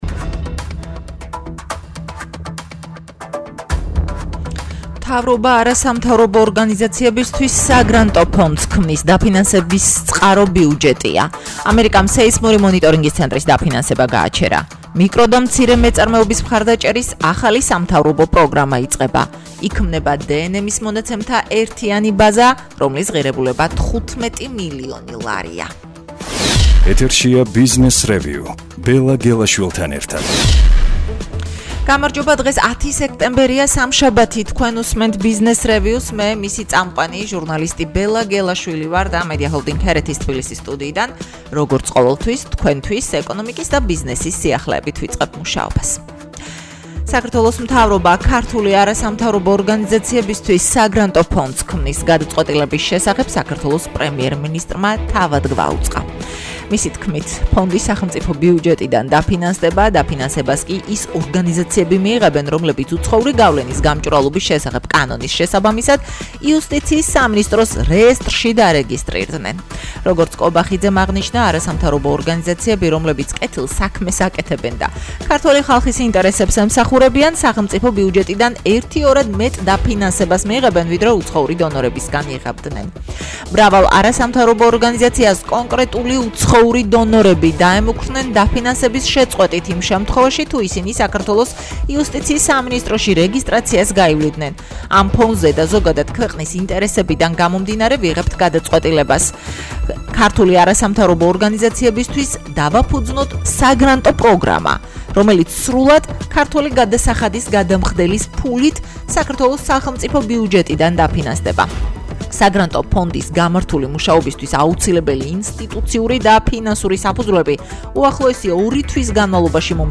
რადიოგადაცემა